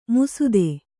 ♪ musude